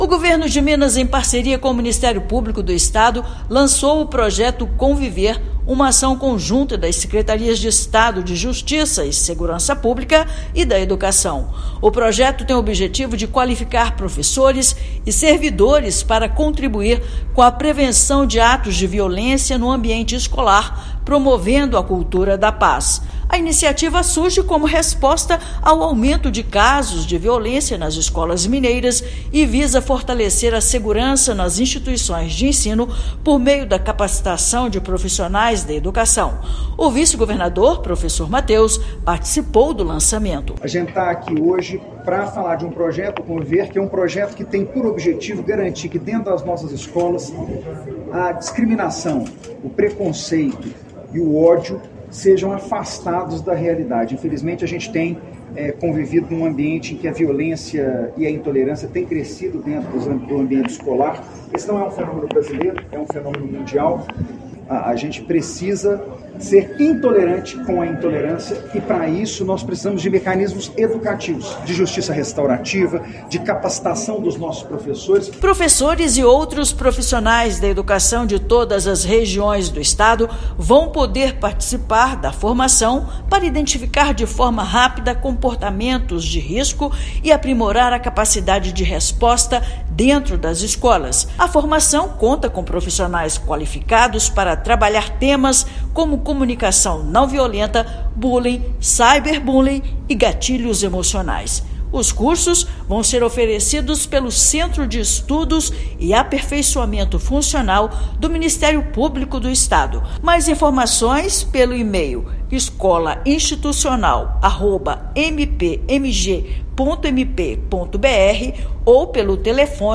[RÁDIO] Governo de Minas e Ministério Público lançam projeto Com Viver para contribuir com a segurança nas escolas
Iniciativa pioneira busca prevenir a violência escolar por meio de formação especializada e promoção de uma cultura de paz. Ouça matéria de rádio.